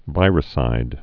(vīrĭ-sīd) also vi·ru·cide (vīrə-)